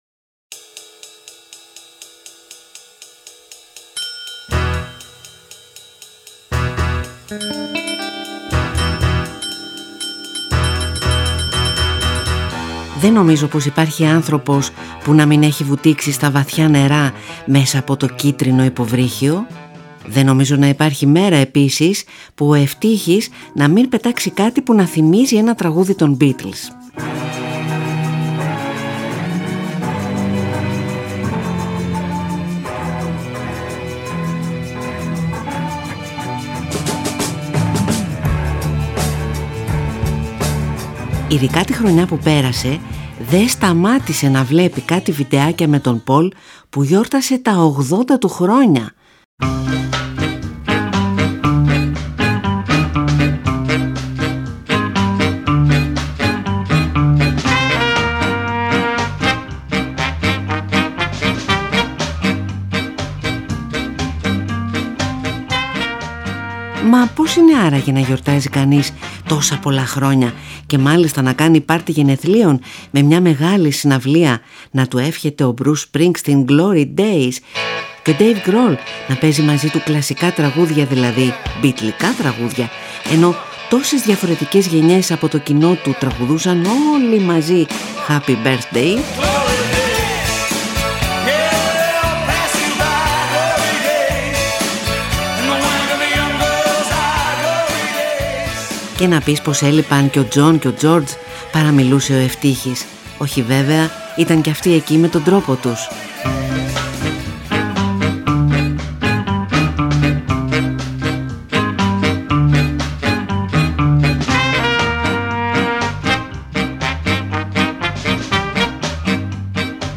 Δίχως τη μουσική των Beatles, του Bruce Springsteen, του Ravi Shankar, του Bill Evans, της Madeleine Peyroux πώς θα έπαιρνε μπροστά το υποβρύχιο;